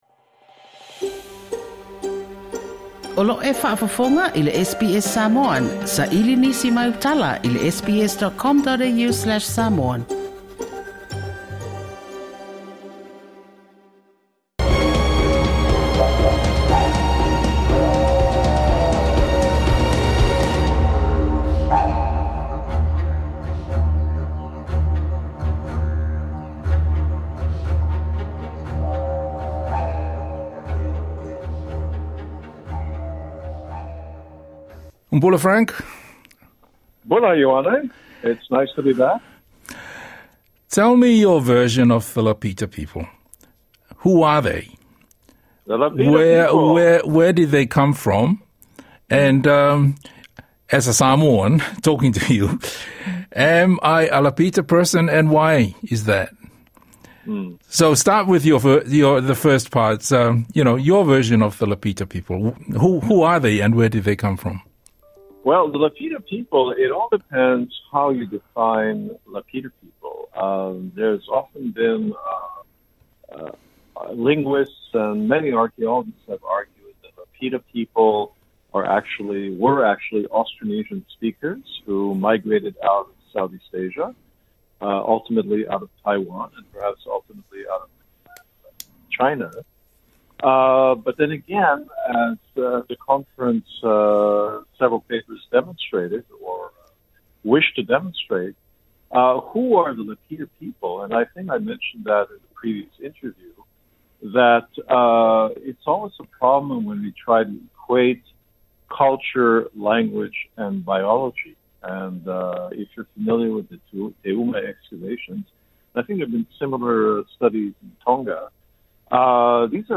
This is part of a series of interviews with academics on the Pacific looking at the Lapita pottery evidence as a way of explaining the migration patterns of Tagata Pasefika.